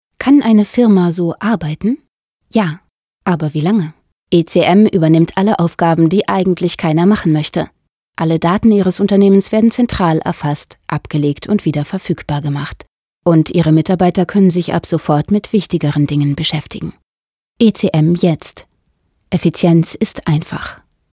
warm, lieblich, frisch, lebendig, hell, überzeugend, leicht
Sprechprobe: Industrie (Muttersprache):